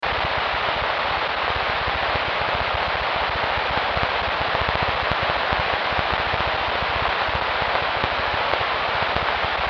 Download Old Radio sound effect for free.
Old Radio